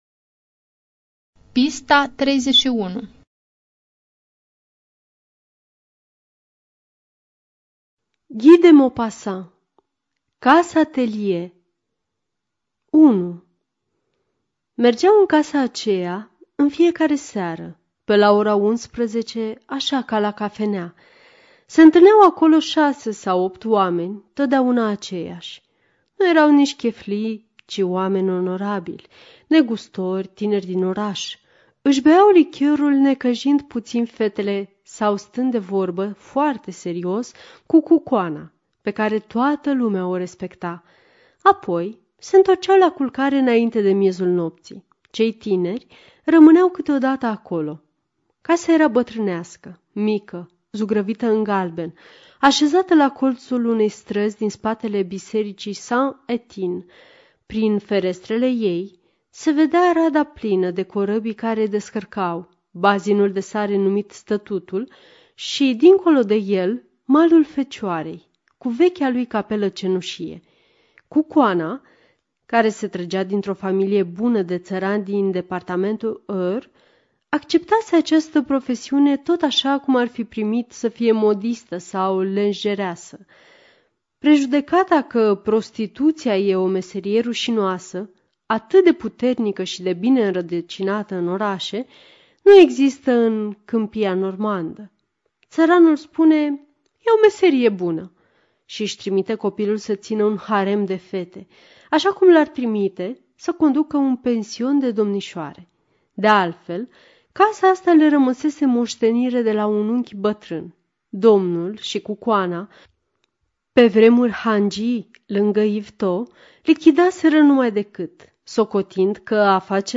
Студия звукозаписиНациональный Информационно-реабилитационный Центр Ассоциации Незрячих Молдовы